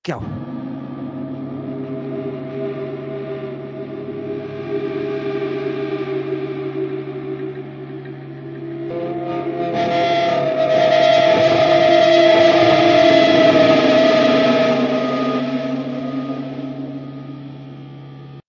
more electric guitar string bending.... sounds like whales, again...